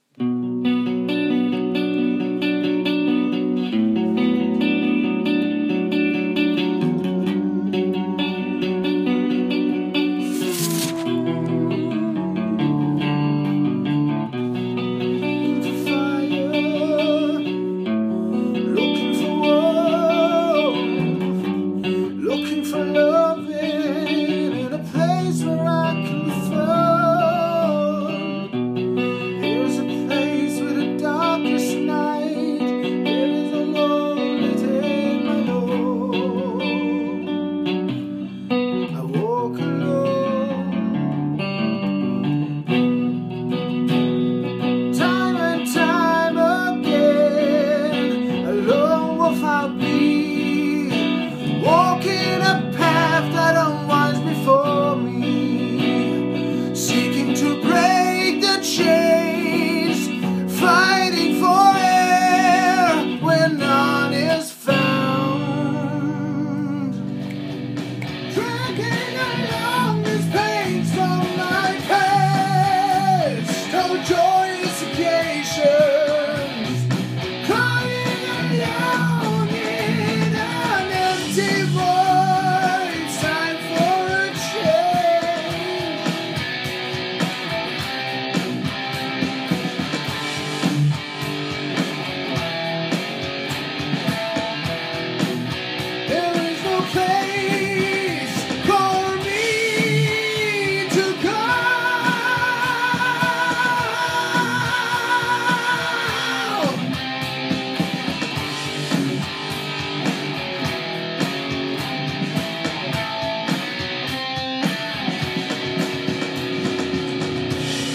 posted 7 years ago Musician and Twitch Streamer Just thought id update so you know that I have not quit doing music. this is just a brainstorm session, where we tried out some vocal on a track we came up with, there are no real lyrics for it yet, so some lines are just gibberish and nonsensical, but we did this to get an idea for what kind of vocal we wanted.
metal guitar vocal